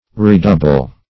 Redouble \Re*dou"ble\, v. i.